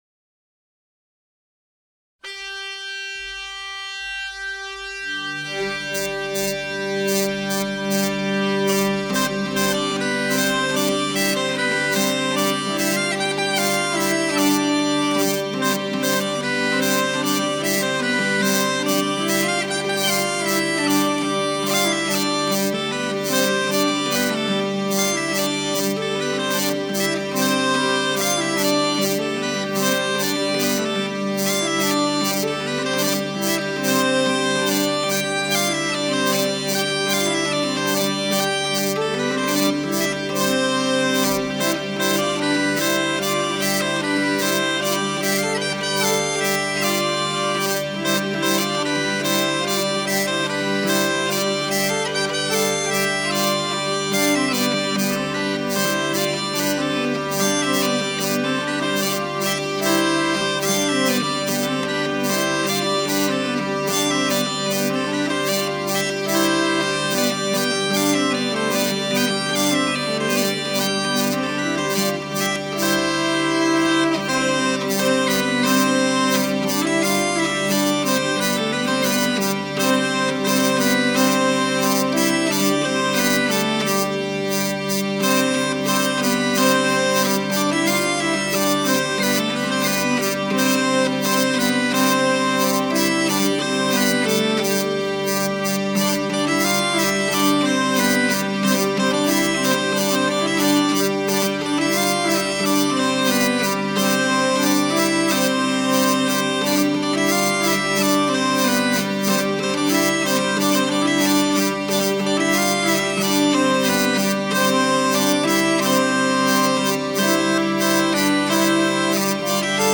Oiartzungo Lezoti estudioan grabatuta.
Zarrabetea